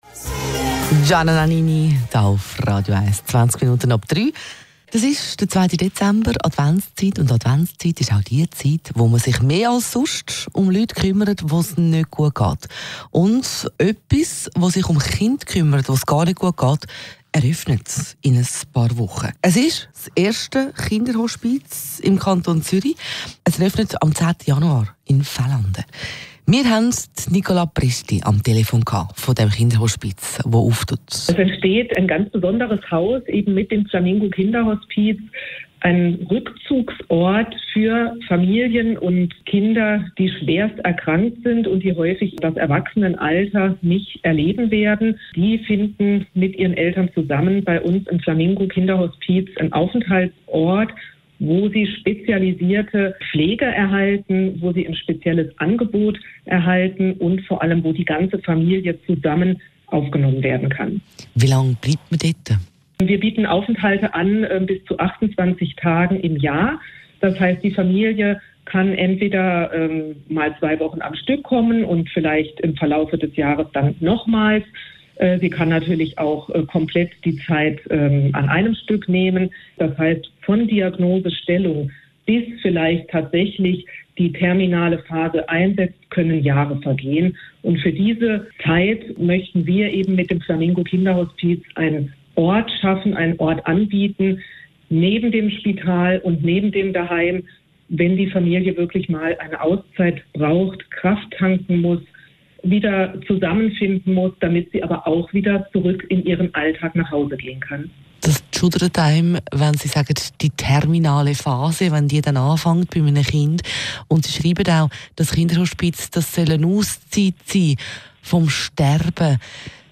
reportage d’actualité